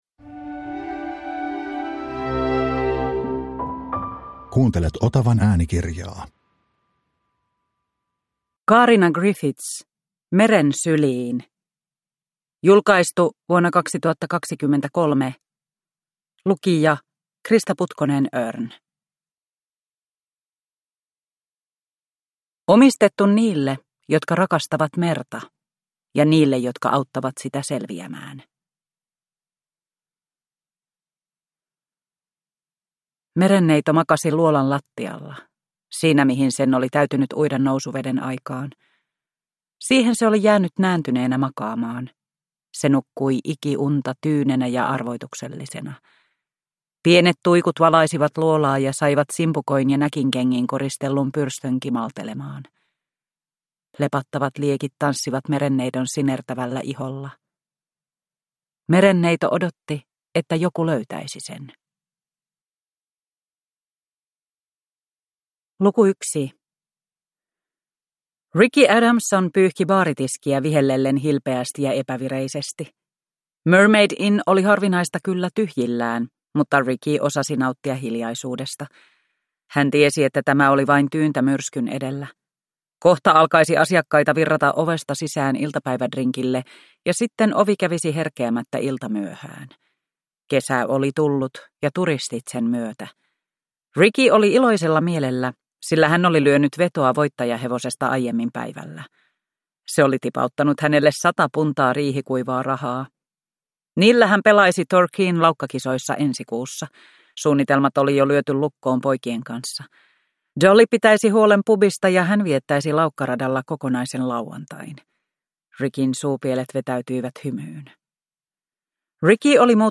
Meren syliin – Ljudbok – Laddas ner